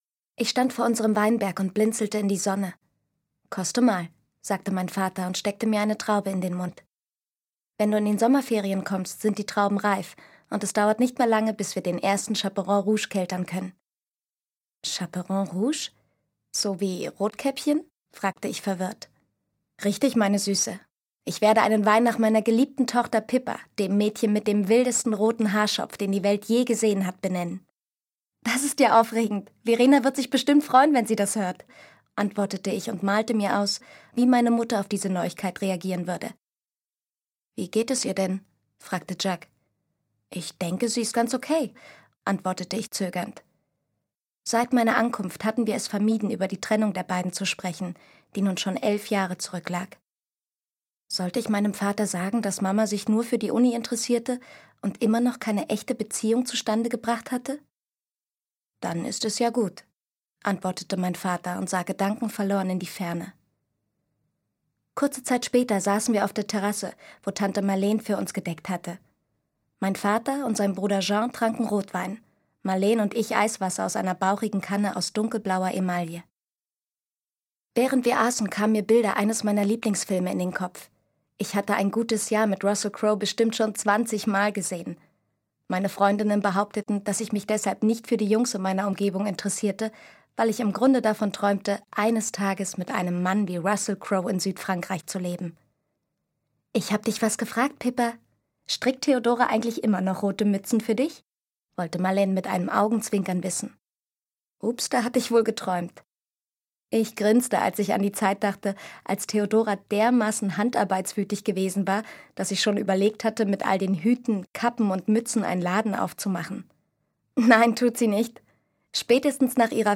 Josefine Preuß (Sprecher)
Die freche Rotkäppchen-Adaption zum 200. Jubiläum der Grimm'schen Kinder- und Hausmärchen - charmant gelesen von Josefine Preuß.